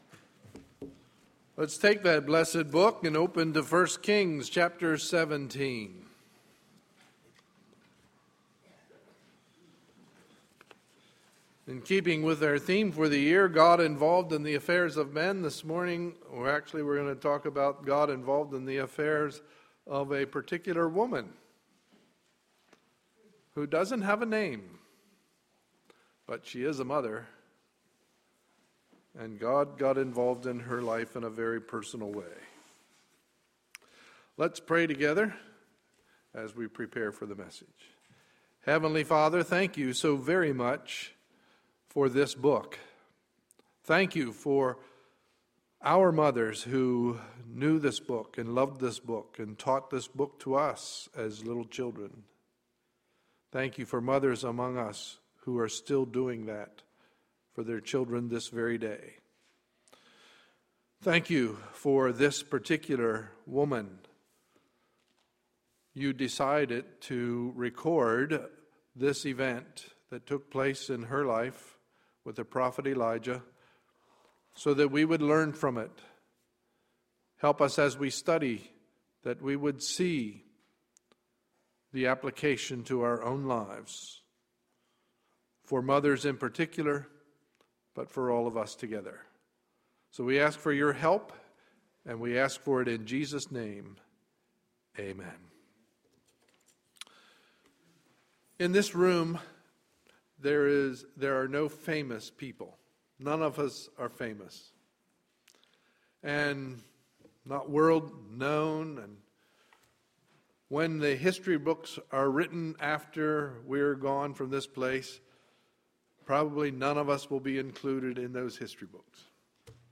Sunday, May 12, 2013 – Morning Service